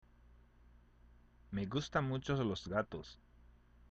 ＜発音と日本語＞